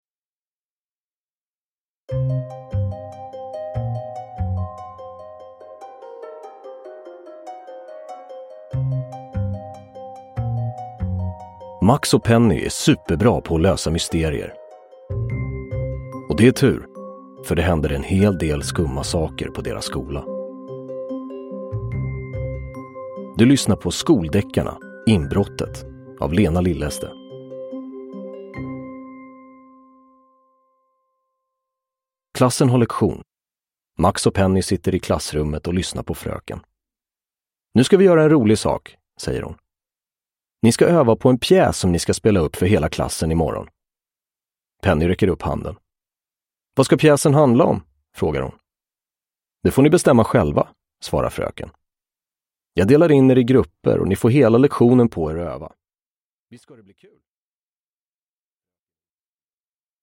Inbrottet – Ljudbok
Inlästa av Anastasios Soulis.
Uppläsare: Anastasios Soulis